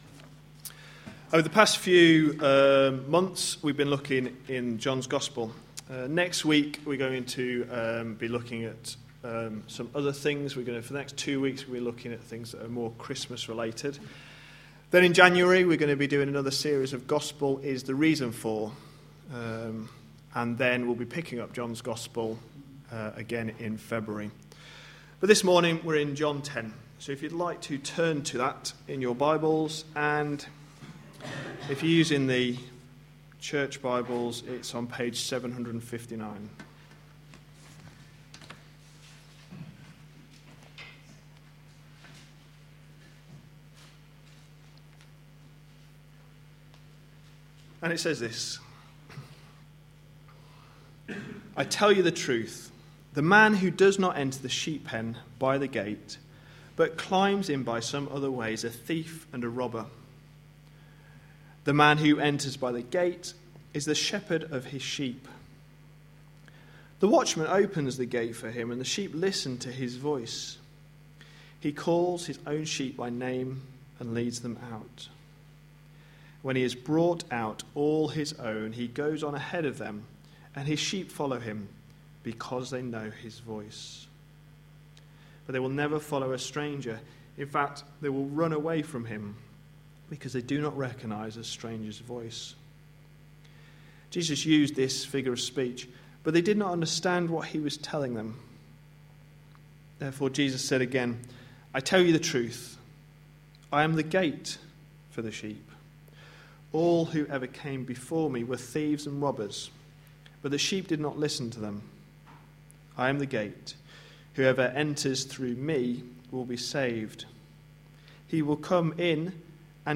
A sermon preached on 8th December, 2013, as part of our The Father's Son series.